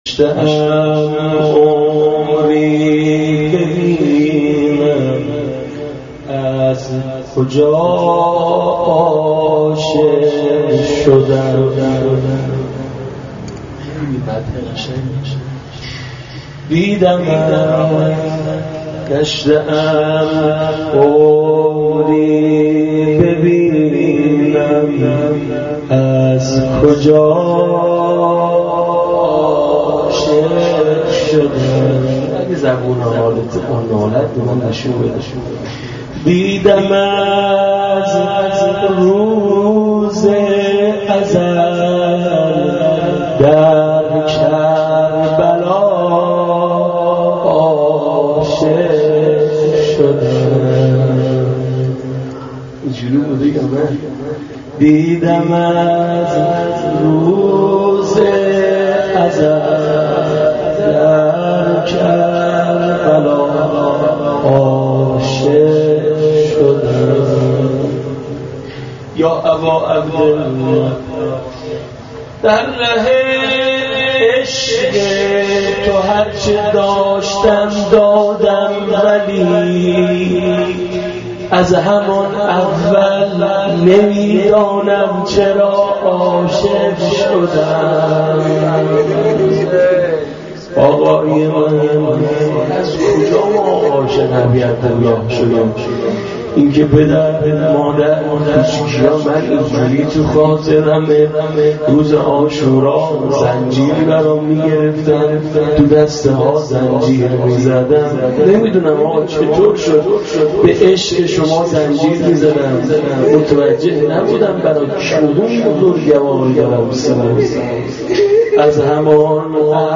گشته ام عمری ببینم از کجا عاشق شدم------مناجات با امام حسین.MP3